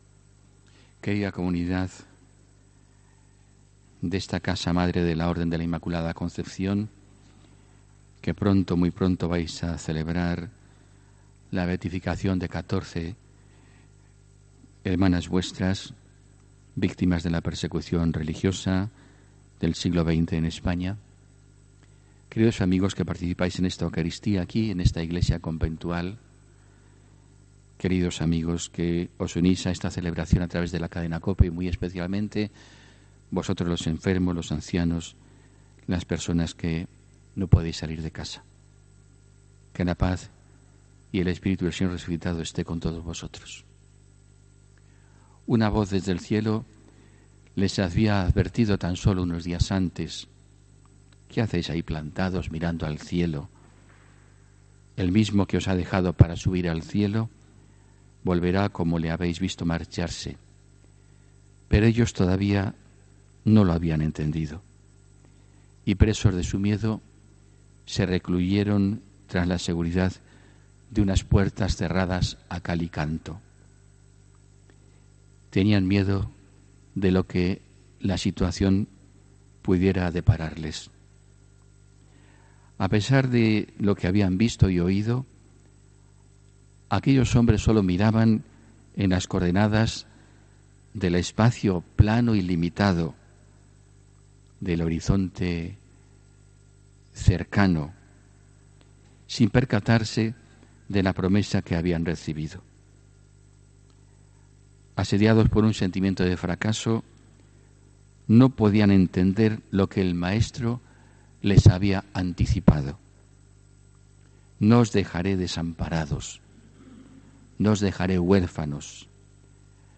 HOMILÍA 9 JUNIO 2019